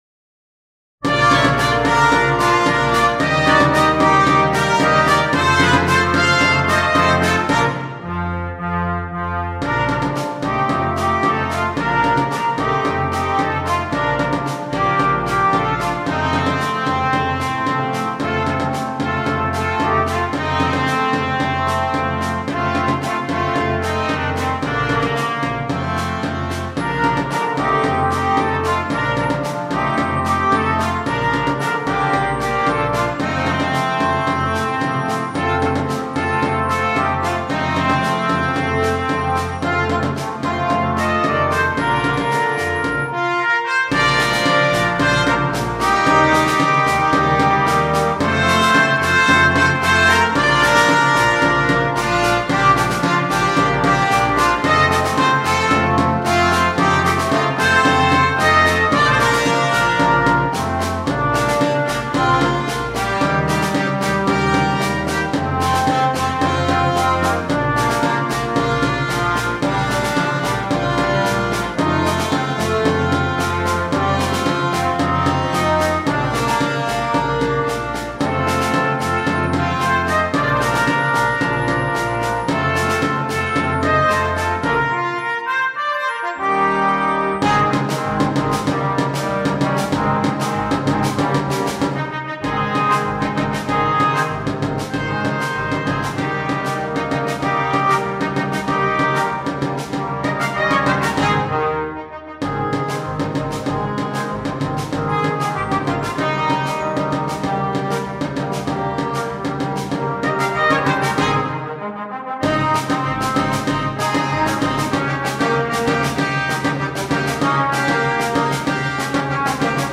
Traditional Mexican
8 Stimmen & Schlagzeug
ohne Soloinstrument
Unterhaltung
Medley